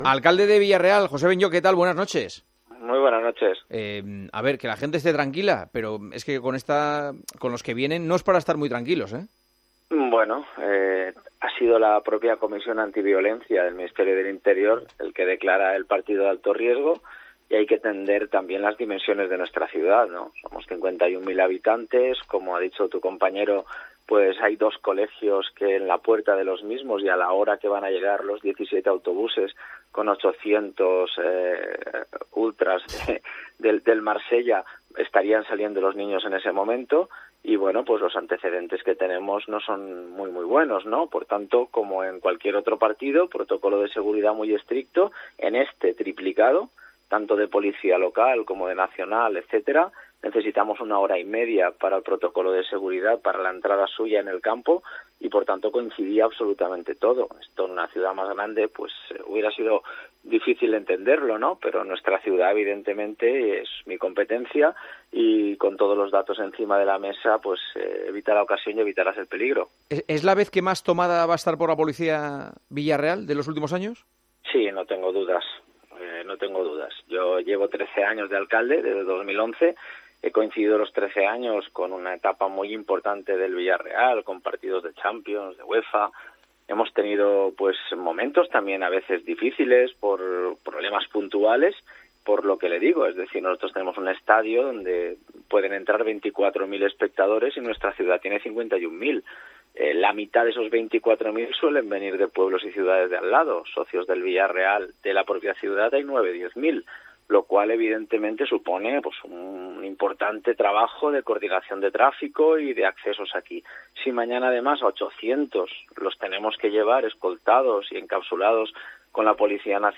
El alcalde de la ciudad, José Benlloch, explicó en El Partidazo de COPE la medida que afectará a los colegios que se encuentren cerca del Estadio de la Cerámica
José Benlloch, alcalde de Villarreal, explica cómo se blinda la ciudad ante la llegada de ultras